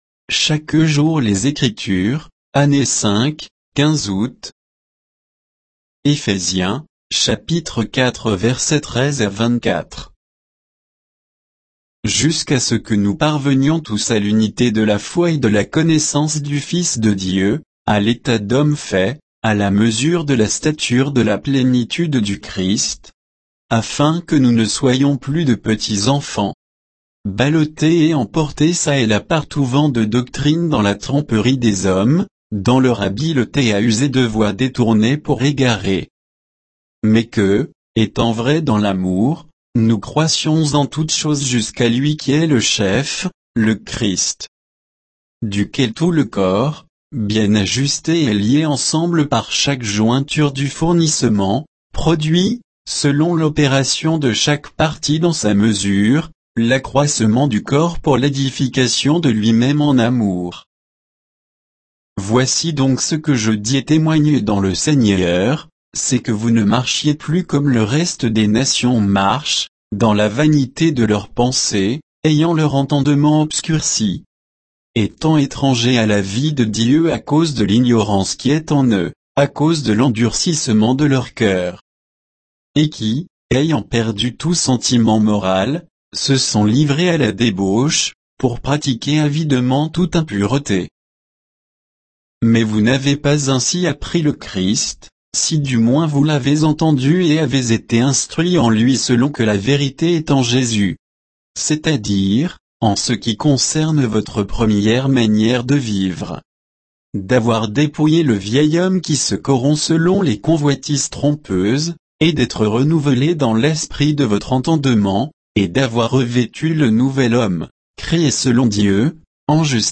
Méditation quoditienne de Chaque jour les Écritures sur Éphésiens 4